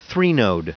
Prononciation du mot threnode en anglais (fichier audio)